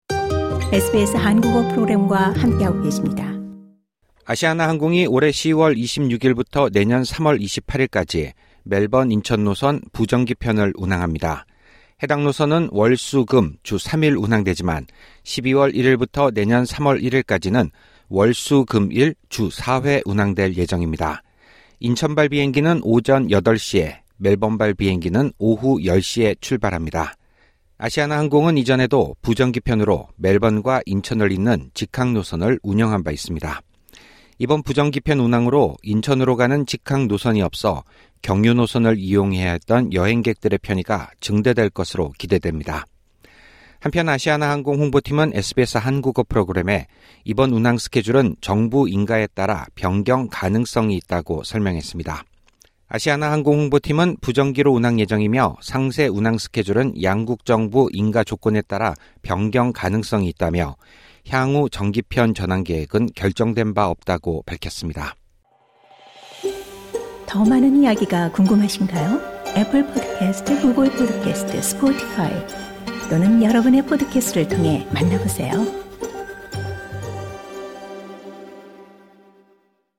SBS Korean 09:21 Korean 상단의 오디오를 재생하시면 뉴스를 들으실 수 있습니다.